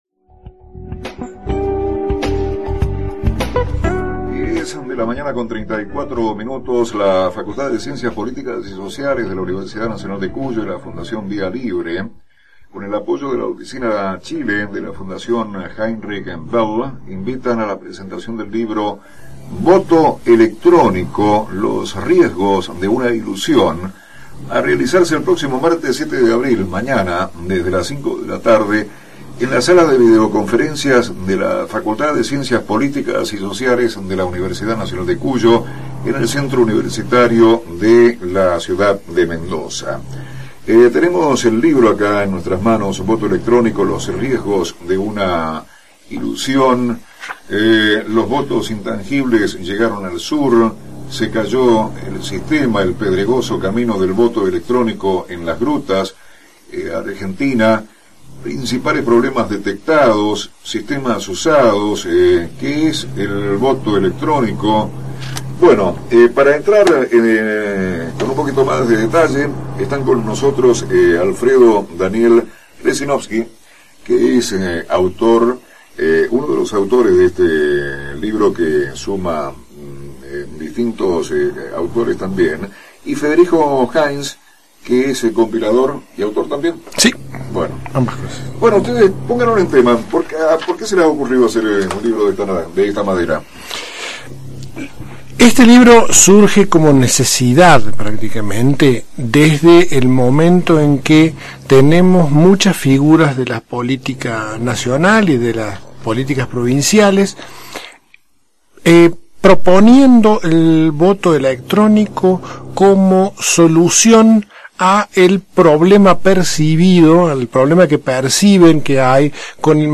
La entrevista de casi media hora de duración se puede bajar en formato mp3.
presentacion_voto_electronico_fm_uncu.mp3